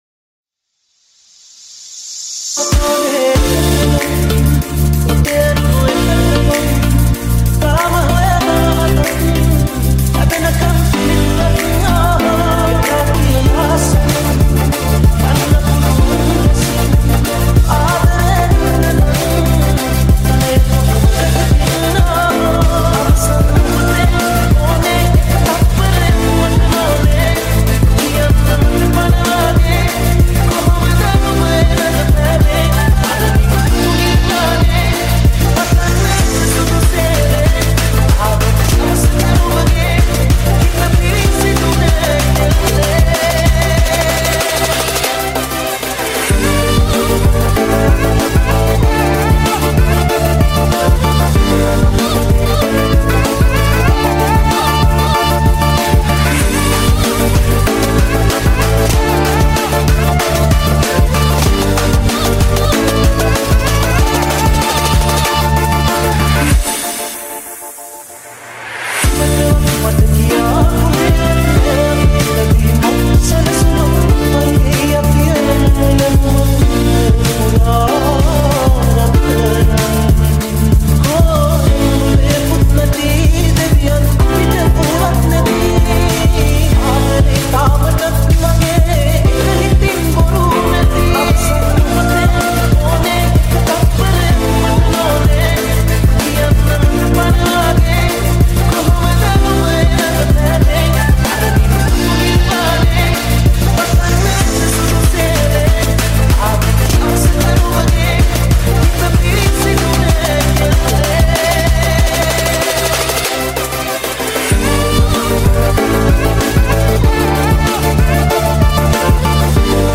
high quality remix